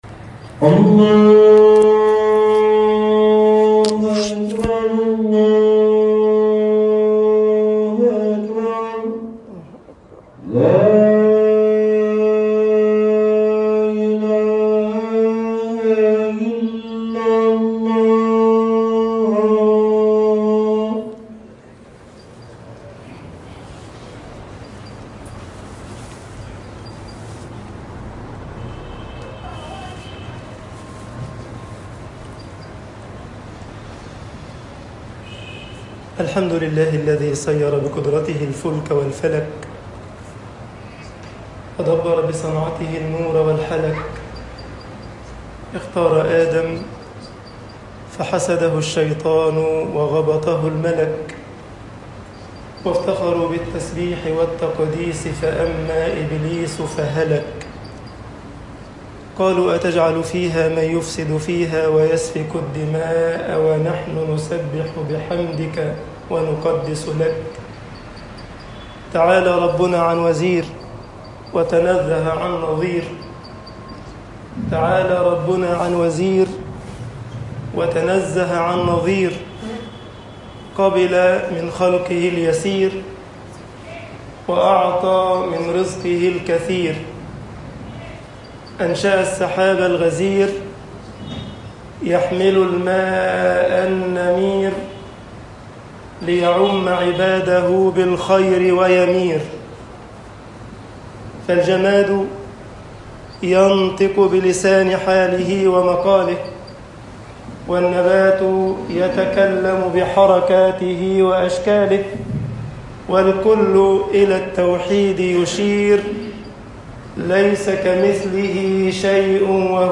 خطب الجمعة - مصر وُعُودُ السَّرَابِ لِلظَمَآنِ طباعة البريد الإلكتروني التفاصيل كتب بواسطة